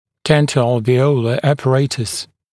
[ˌdentə(u)əlvɪ’əulə ˌæpə’reɪtəs][ˌдэнто(у)элви’оулэ ˌэпэ’рэйтэс]зубоальвеолярный комплекс